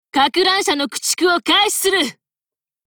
文件:Cv-20702 warcry 2.mp3 - 萌娘共享